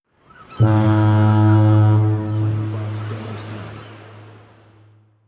“HarboHorn” Clamor Sound Effect
Can also be used as a car sound and works as a Tesla LockChime sound for the Boombox.